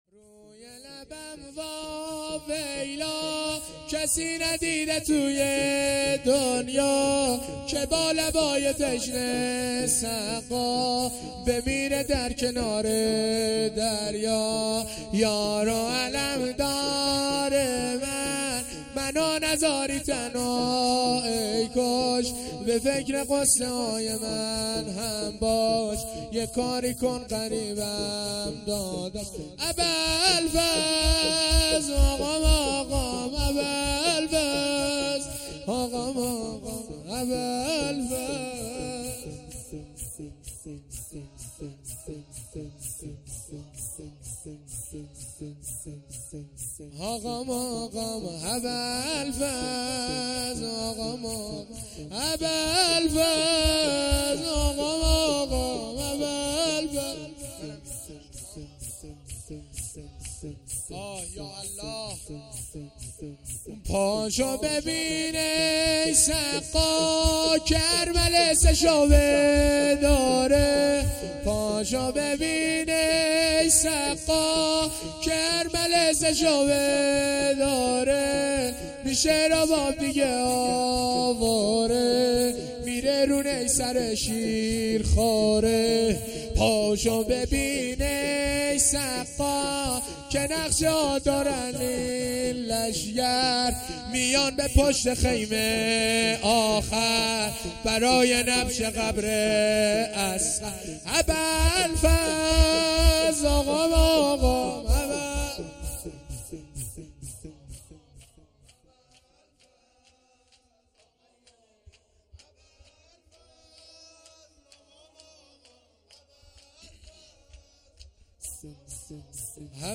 شور
محرم الحرام ۱۴۴۳